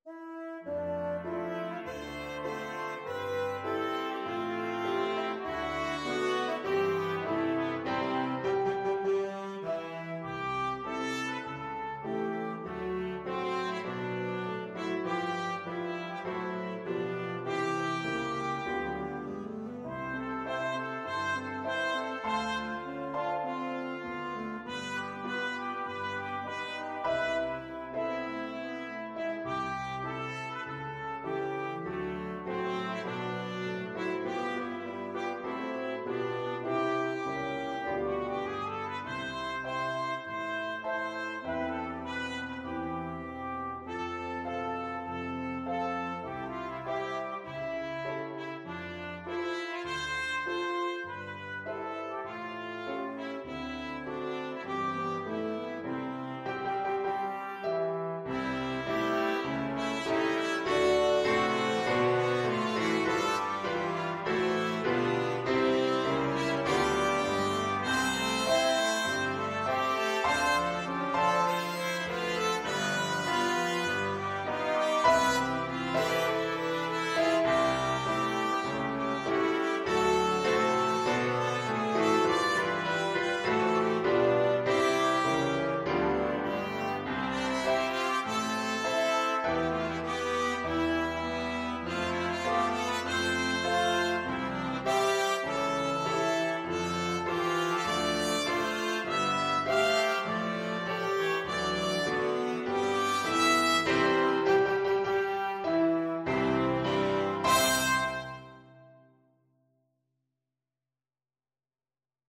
4/4 (View more 4/4 Music)
Moderato = c. 100